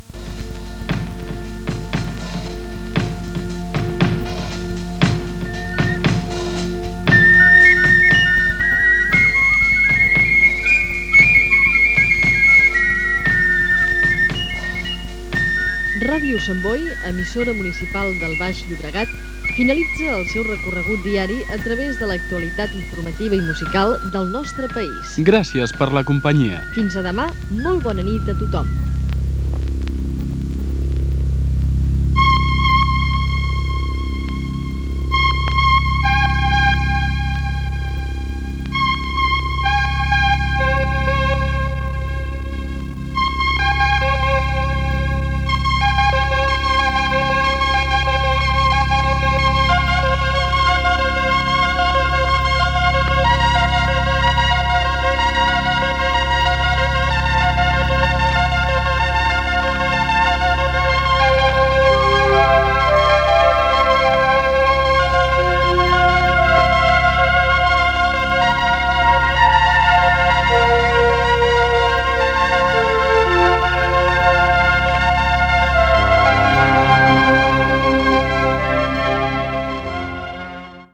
Tancament d'emissió